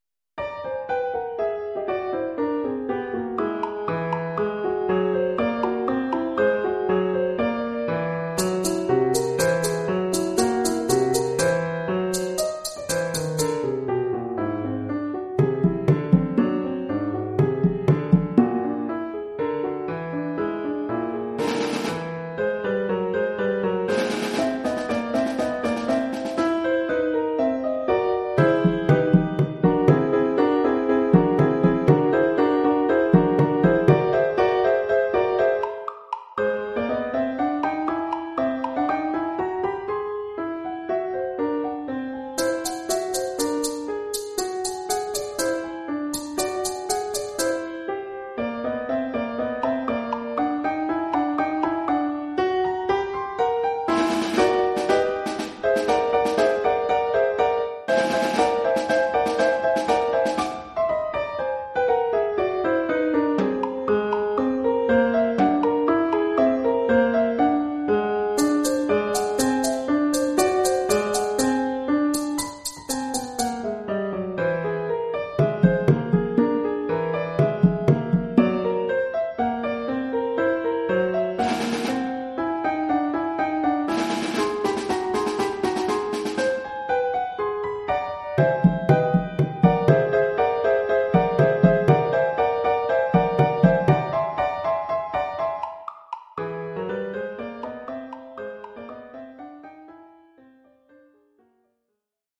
Collection : Percussions
Oeuvre pour percussions et piano.